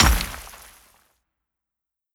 Hit_Concrete 03.wav